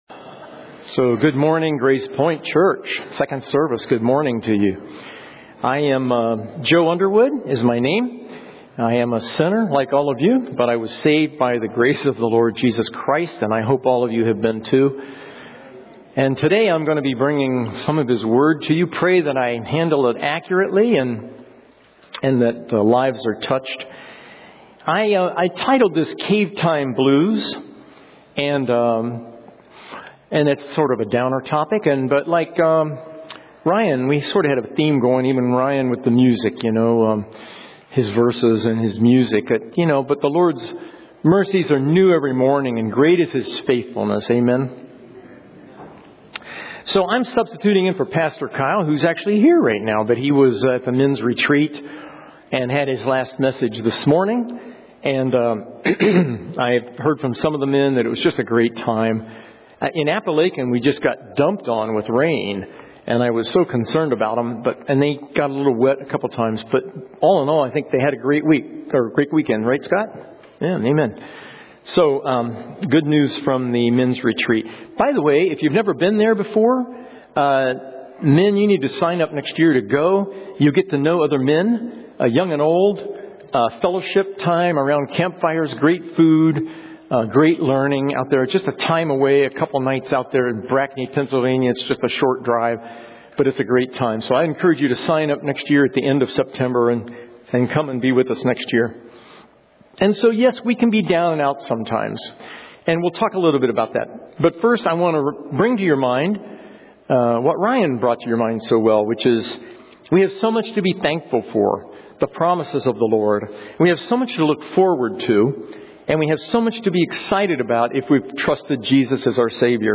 Series: 2024 Sermons